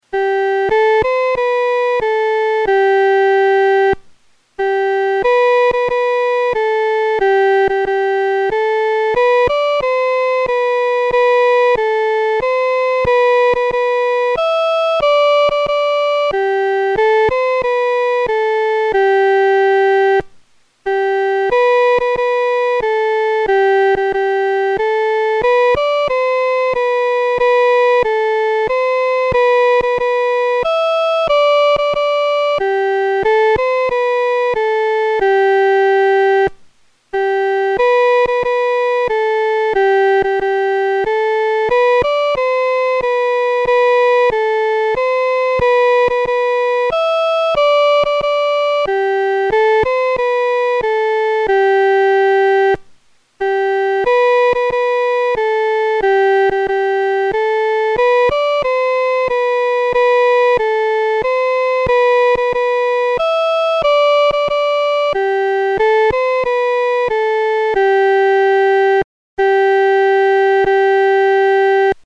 女高